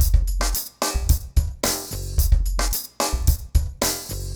RemixedDrums_110BPM_43.wav